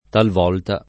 talvolta [ talv 0 lta ]